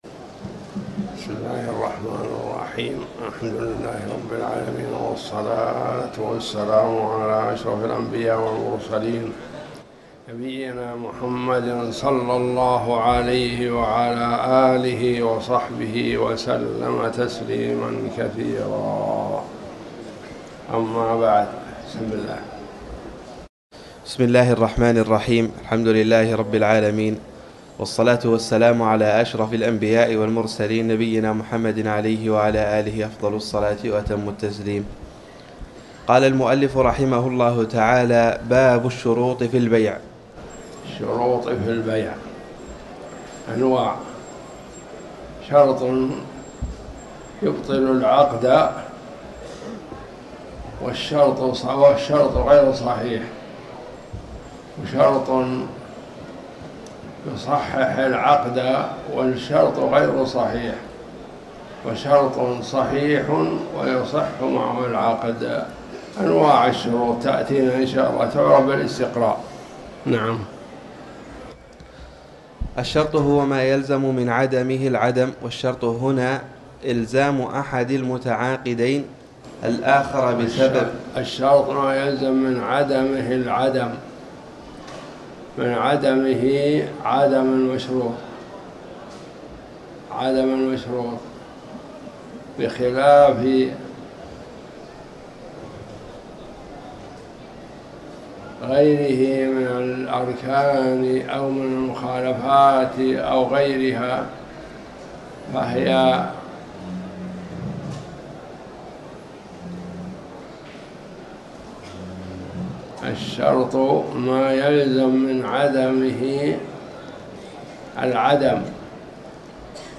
تاريخ النشر ٧ ربيع الثاني ١٤٤٠ هـ المكان: المسجد الحرام الشيخ